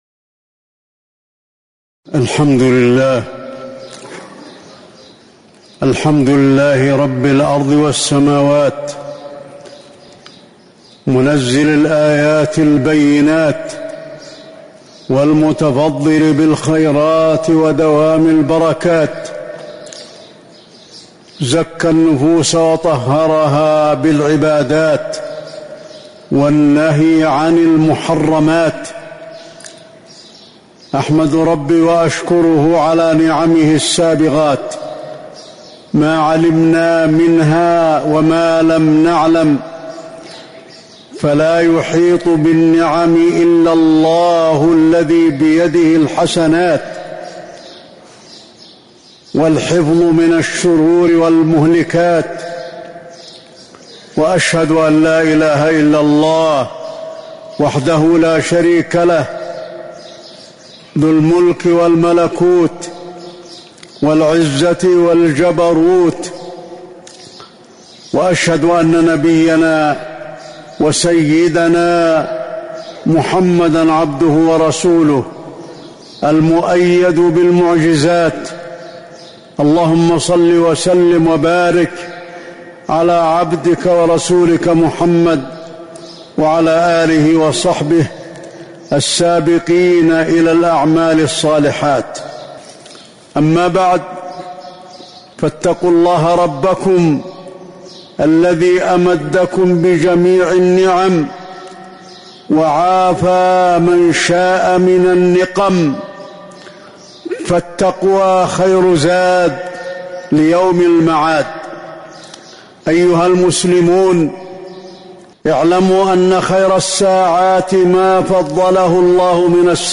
تاريخ النشر ٩ رمضان ١٤٤٤ هـ المكان: المسجد النبوي الشيخ: فضيلة الشيخ د. علي بن عبدالرحمن الحذيفي فضيلة الشيخ د. علي بن عبدالرحمن الحذيفي رمضان شهر العبادات